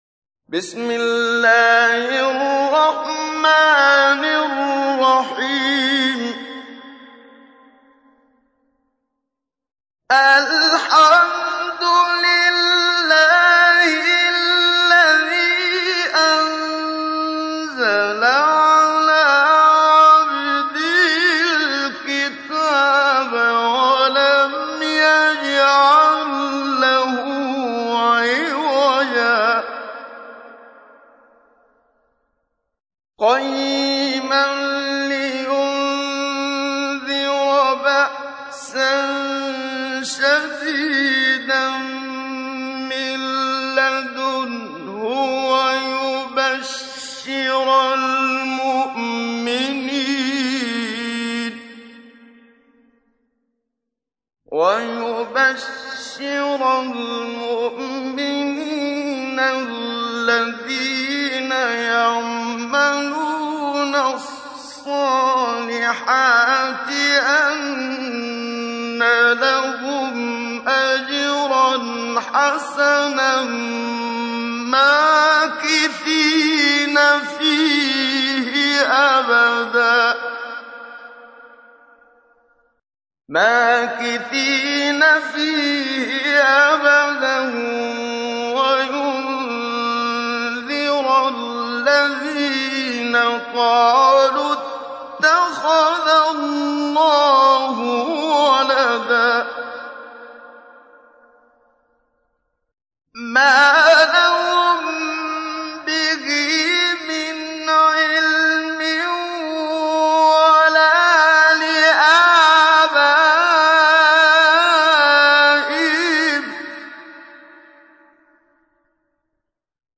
تحميل : 18. سورة الكهف / القارئ محمد صديق المنشاوي / القرآن الكريم / موقع يا حسين